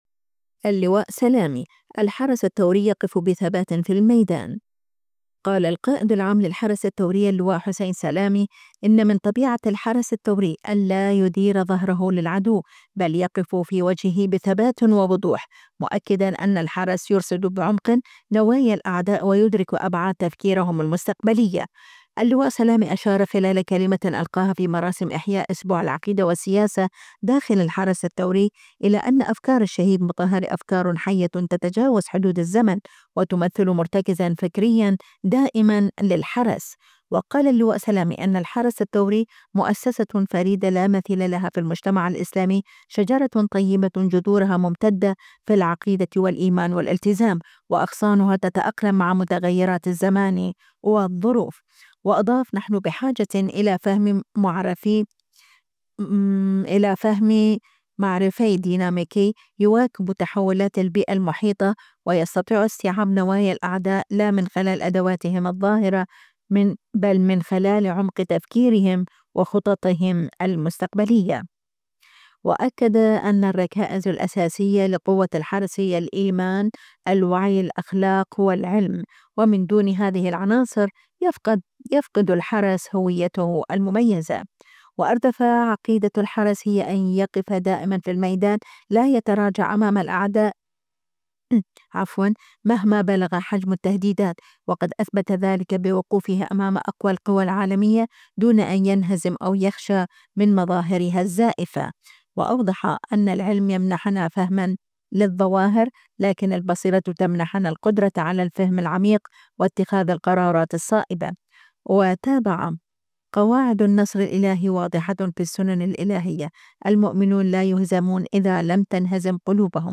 اللواء سلامي أشار خلال كلمة ألقاها في مراسم إحياء "أسبوع العقيدة والسياسة" داخل الحرس الثوري،  إلى أن "أفكار الشهيد مطهري أفكار حيّة تتجاوز حدود الزمن، وتمثل مرتكزًا فكريًا دائمًا للحرس".